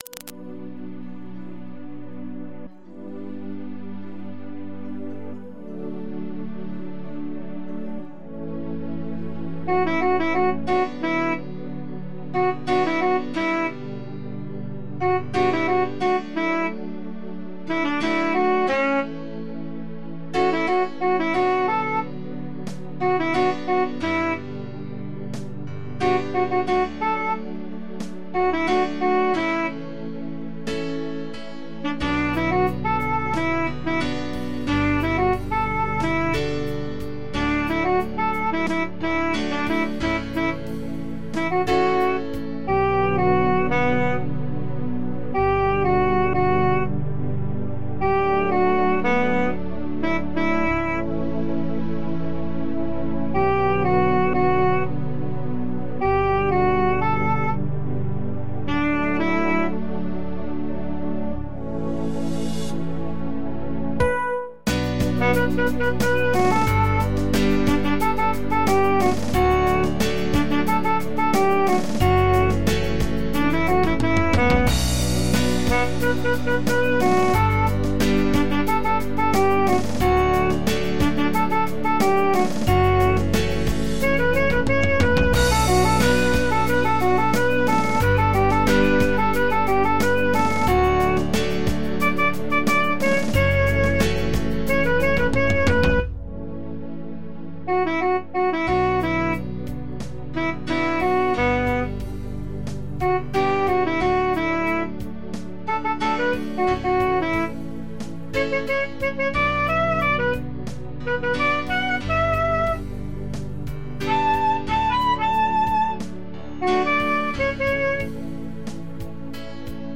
Karaoke Tracks